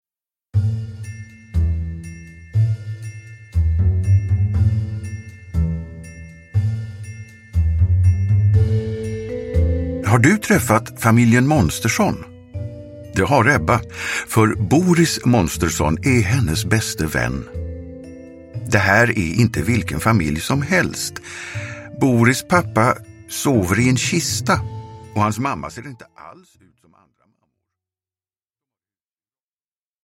Ett monster sover över – Ljudbok – Laddas ner